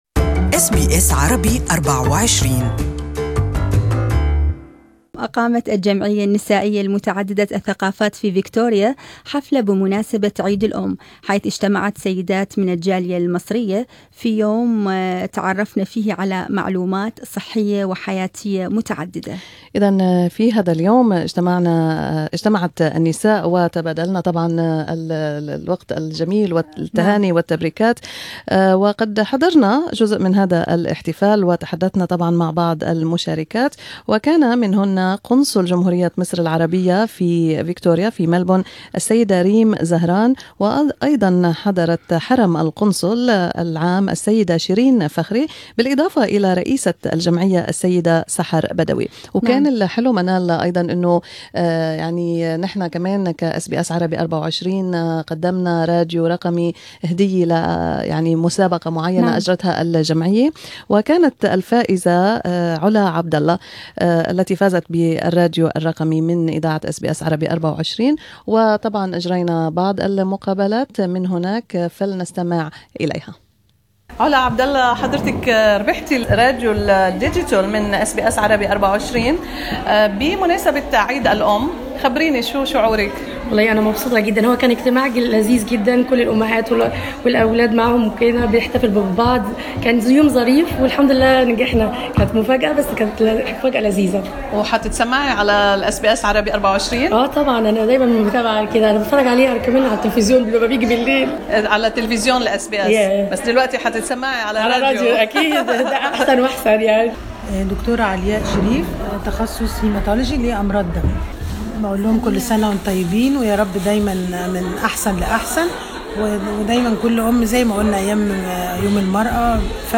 Interviews from the celebration of The Women's Multicultural Association in Melbourne for Mother's Day.